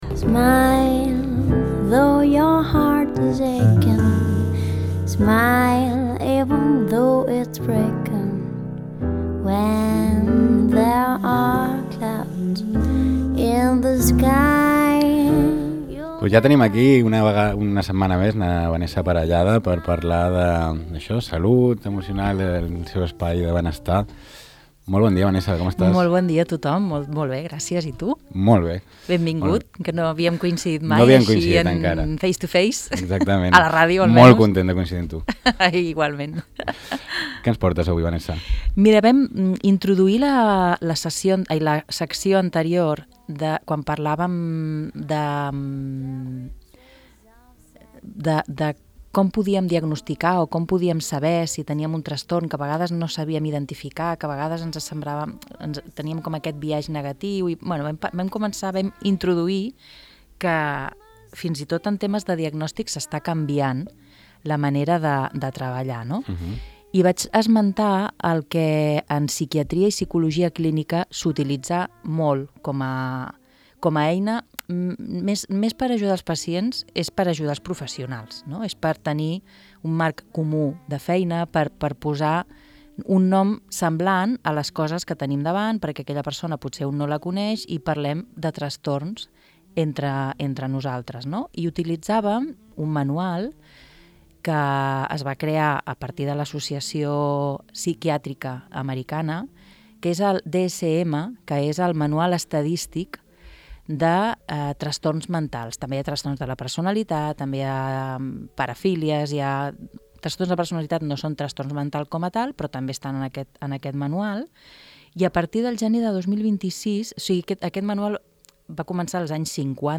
En aquesta conversa posa el focus en com s’avalua la salut mental avui dia, el paper del manual diagnòstic DSM i el debat actual dins la comunitat científica per avançar cap a models més flexibles que tinguin en compte factors socials, culturals i personals.